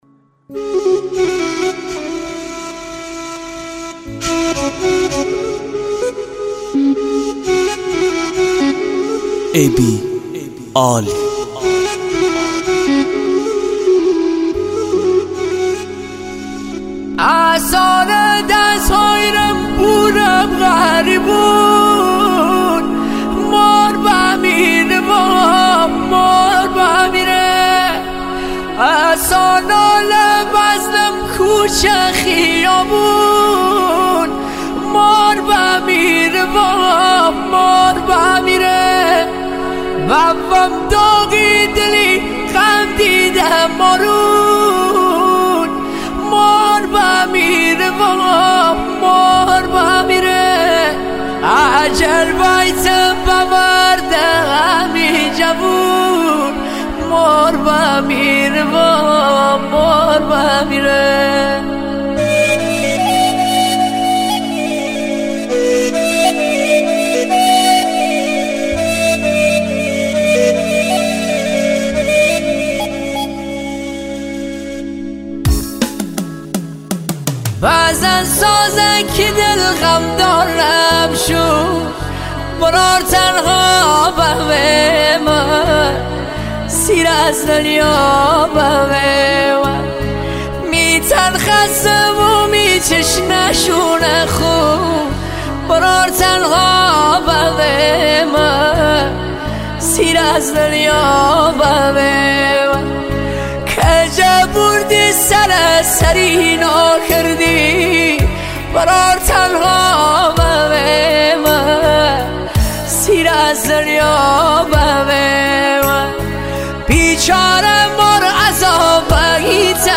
اشکم در اوردی مرد با این سوز صدا